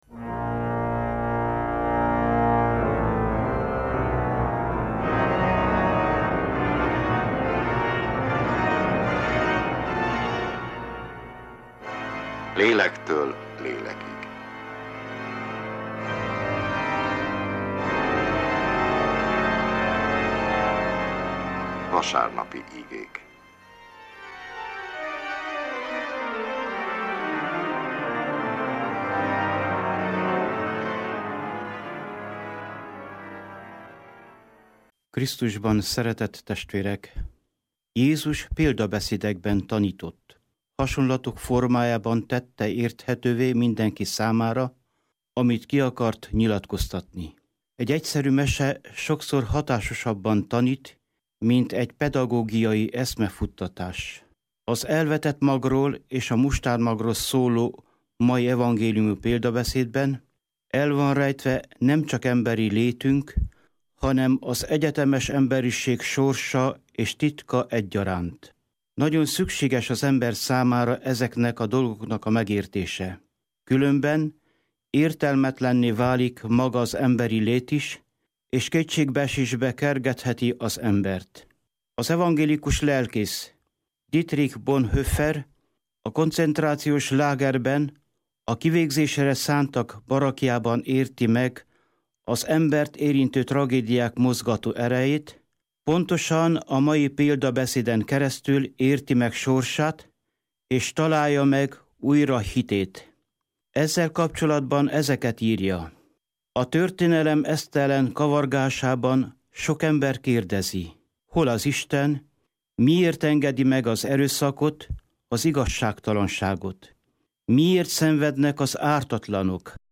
Katolikus igehirdetés, június 14.